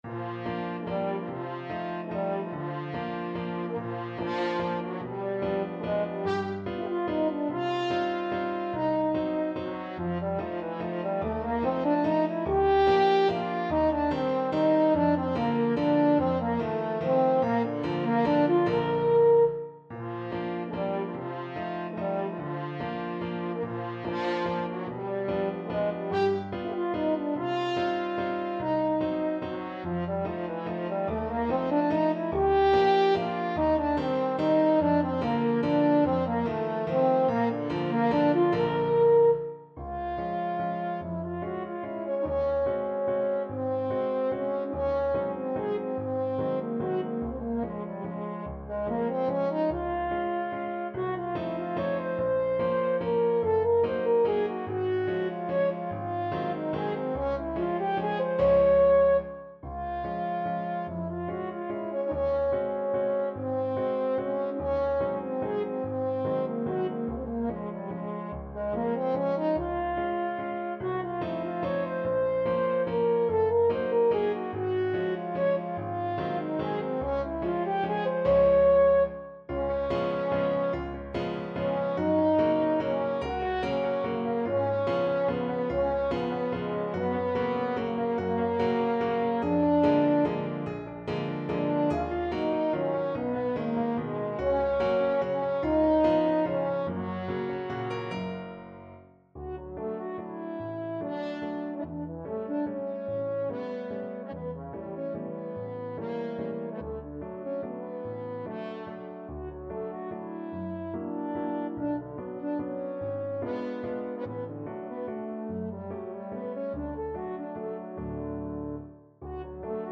French Horn
=145 Presto (View more music marked Presto)
3/4 (View more 3/4 Music)
Bb major (Sounding Pitch) F major (French Horn in F) (View more Bb major Music for French Horn )
Classical (View more Classical French Horn Music)